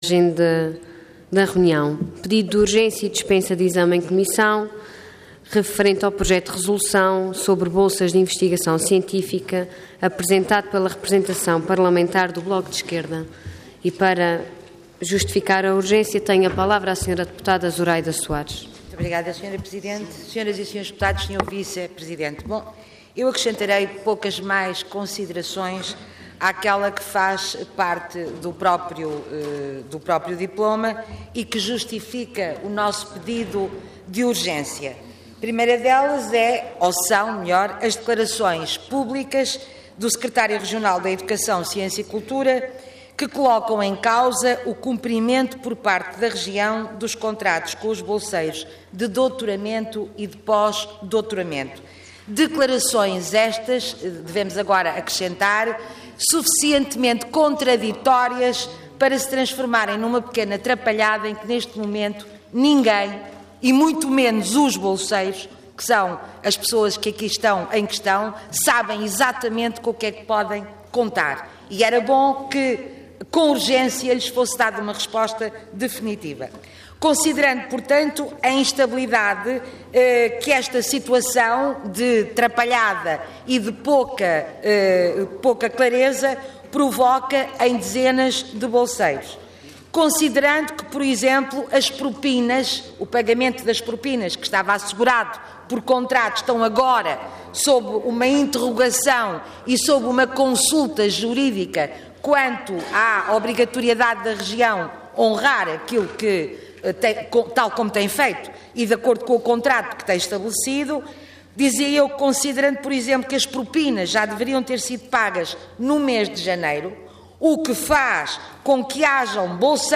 Detalhe de vídeo 22 de fevereiro de 2013 Download áudio Download vídeo Diário da Sessão Processo X Legislatura Bolsas de investigação científica. Intervenção Pedido de urgência e dispensa de exame em comissão Orador Zuraida Soares Cargo Deputado Entidade BE